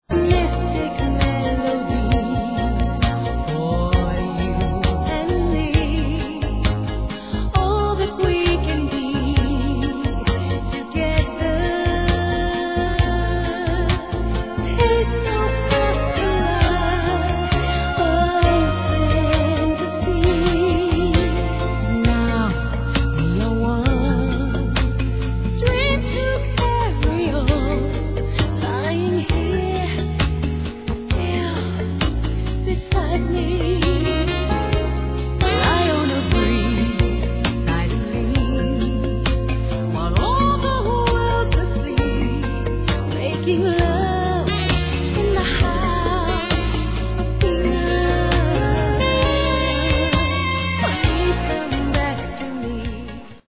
Der Softsong